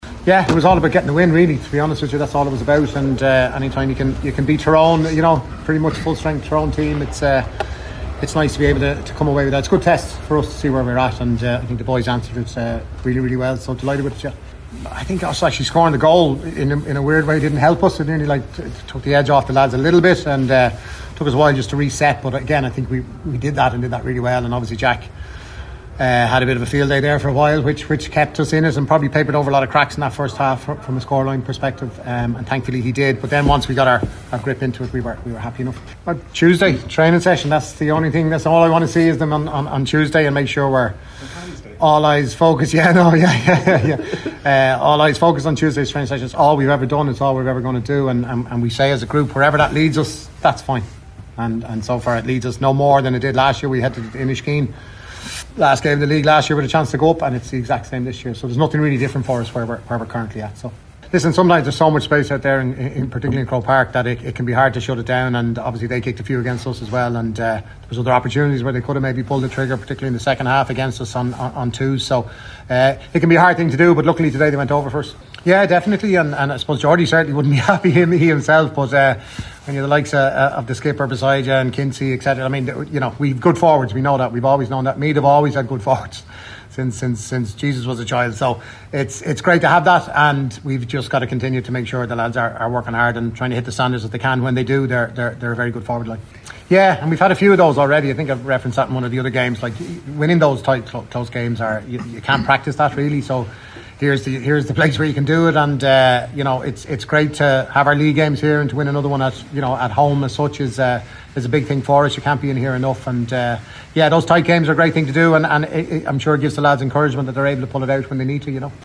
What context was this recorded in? was happy with the performance when he spoke to the media after the win…